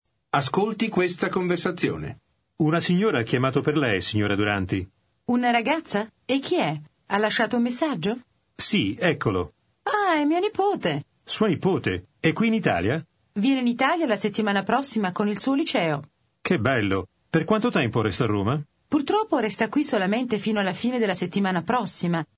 Аудио курс для самостоятельного изучения итальянского языка.